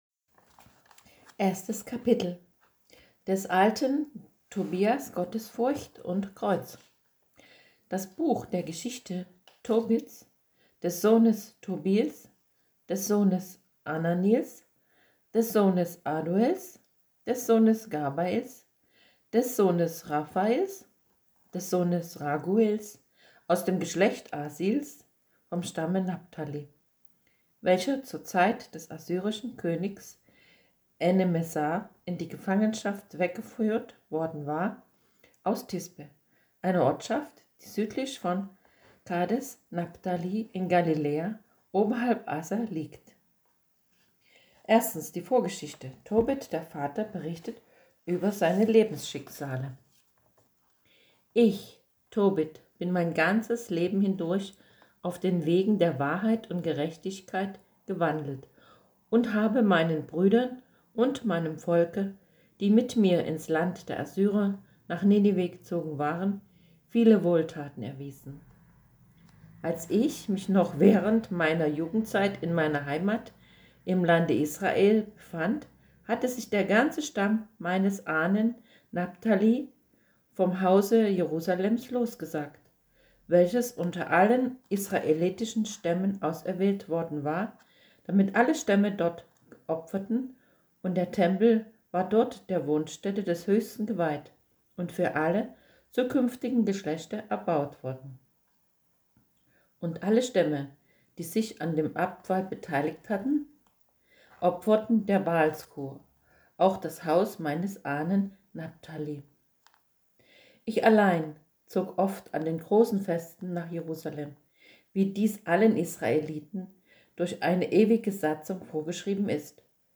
Diktiergeraet_421.mp3